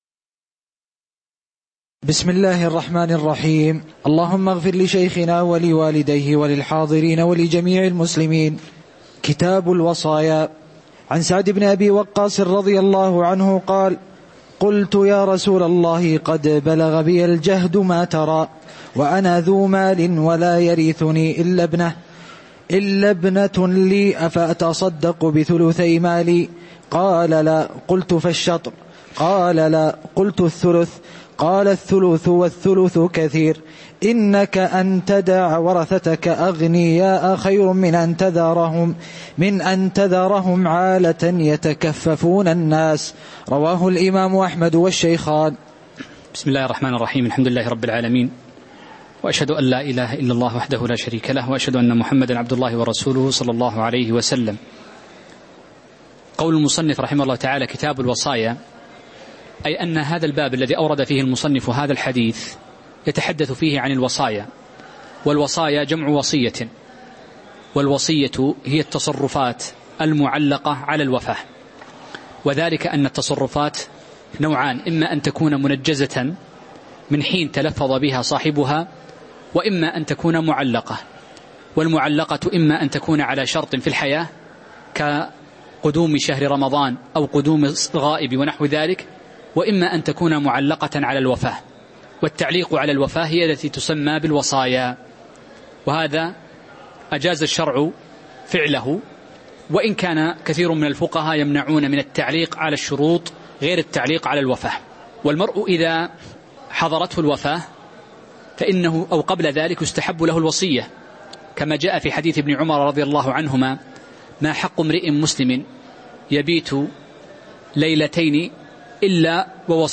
تاريخ النشر ١ شعبان ١٤٤٠ هـ المكان: المسجد النبوي الشيخ